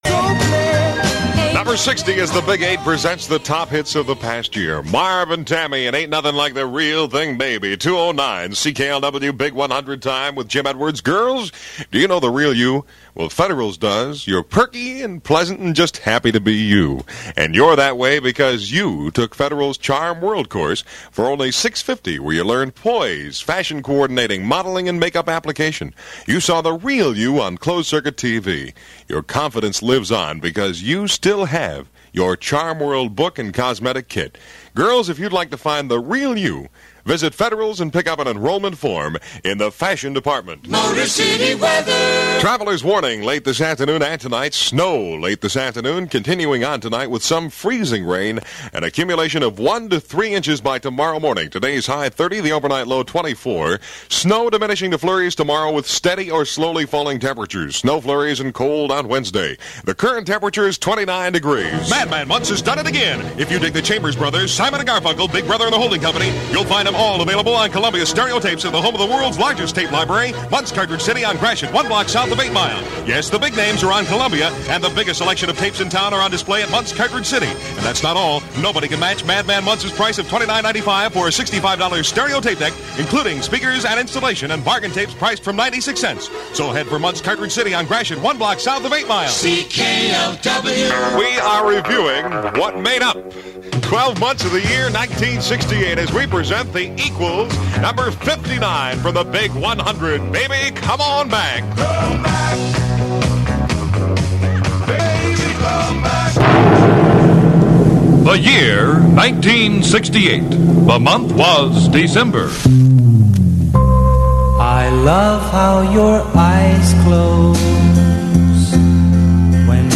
It was Canadian, but sounded American - and folks on both sides of the border loved it.